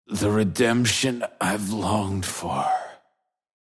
死亡语音